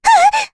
Rehartna-Vox_Damage_kr_03.wav